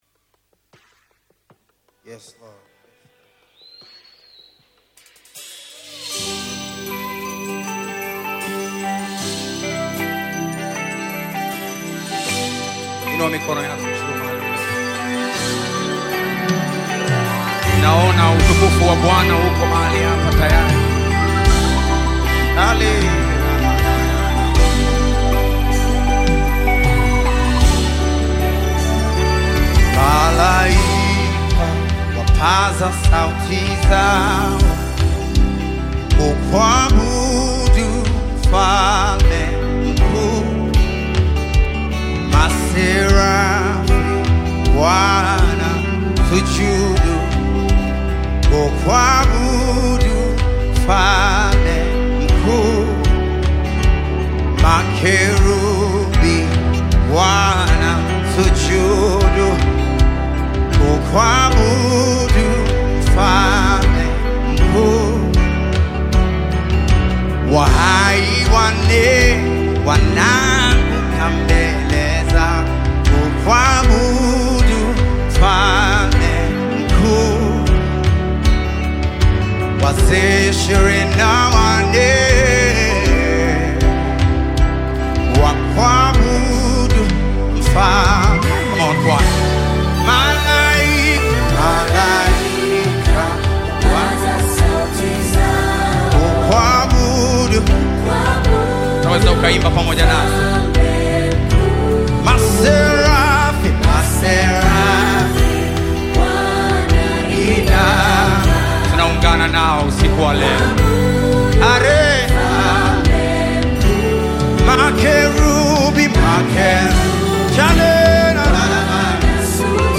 Nyimbo za Dini music
Tanzanian Gospel